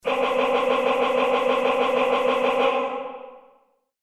Auch das Staccato weiß zu überzeugen, solange nicht zu oft der gleiche Ton zu hören ist:
männer-machinegun.mp3